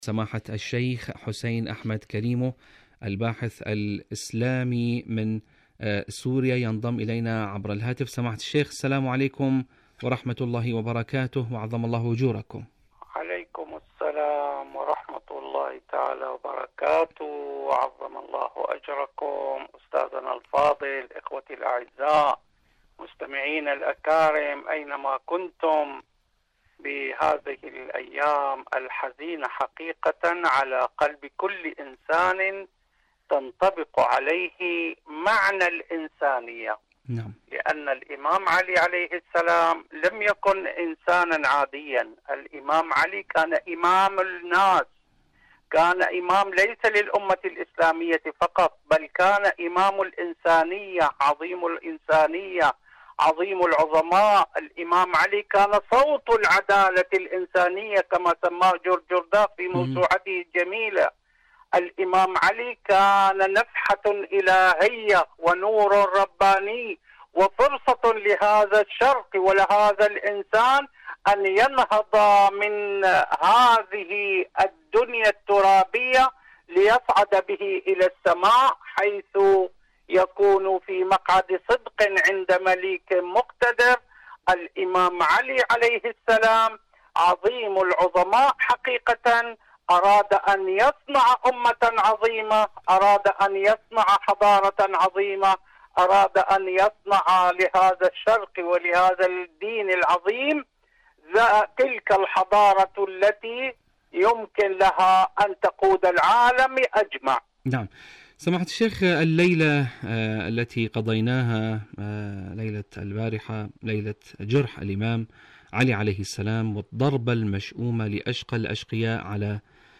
مقابلات إذاعية برنامج المحراب الدامي جرح الإمام علي عليه السلام